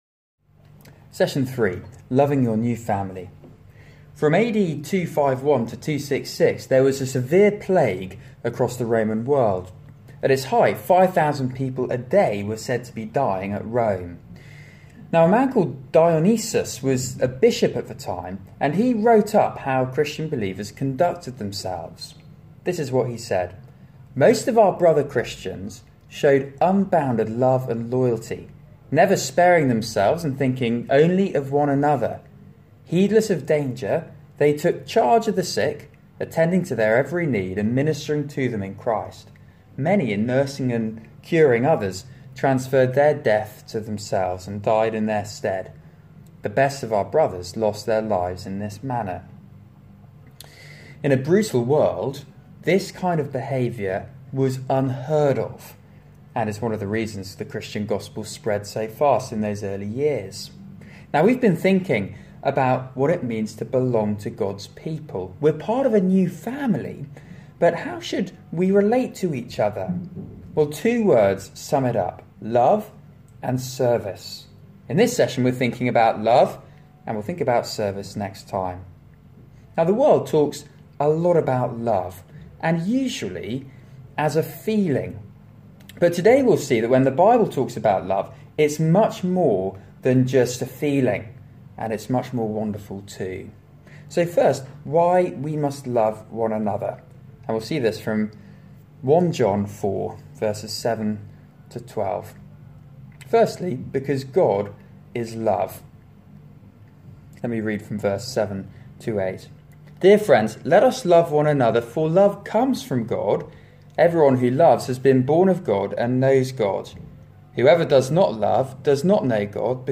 Media for Christian Foundations on Mon 09th Oct 2017 19:30 Speaker: [unset] Passage: Series: Discipleship Course- Being God's people today Theme: Loving your new family Talk Search the media library There are recordings here going back several years.